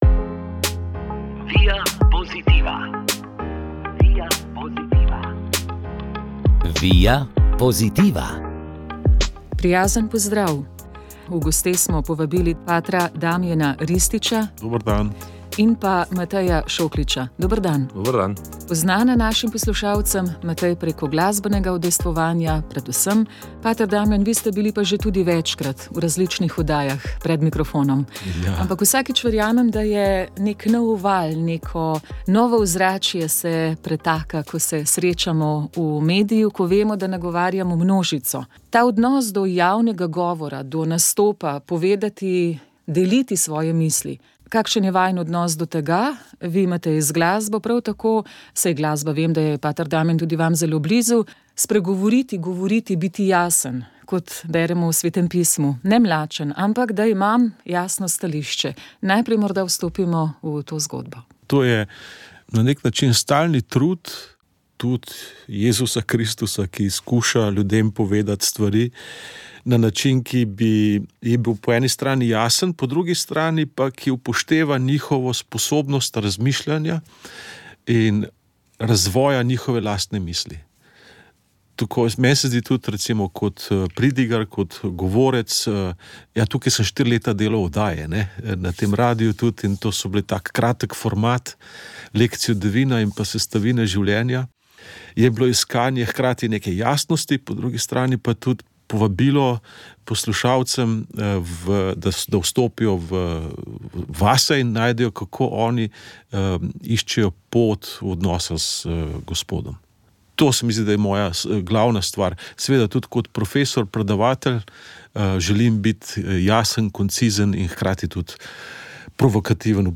družba duhovnost pogovor odnosi vzgoja